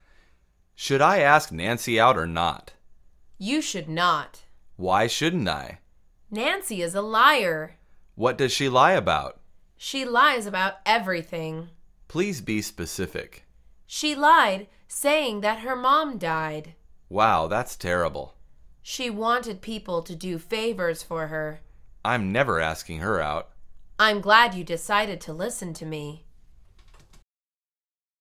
مجموعه مکالمات ساده و آسان انگلیسی – درس شماره یکم از فصل دوستیابی: باید من